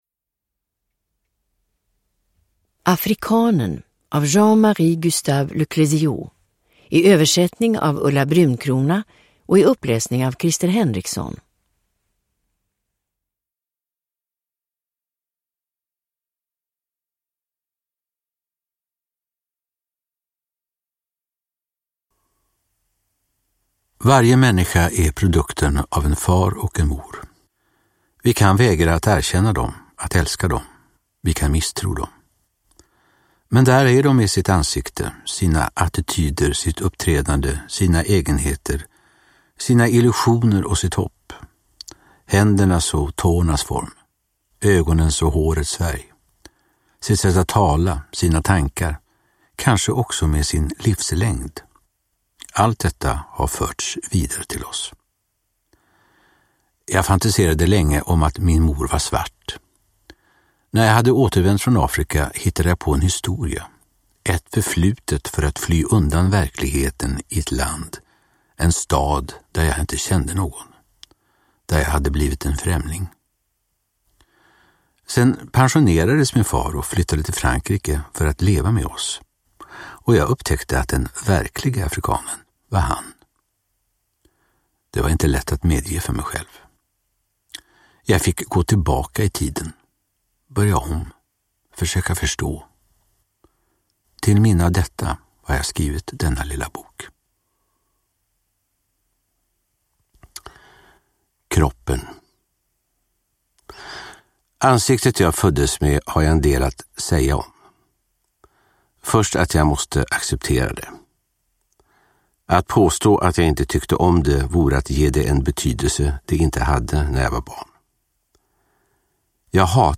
Afrikanen : Porträtt av en far (ljudbok) av Jean-Marie Gustave Le Clézio